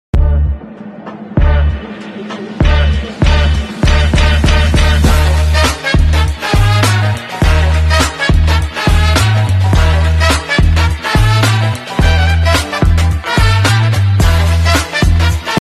John Deere Tractor John Deere Sound Effects Free Download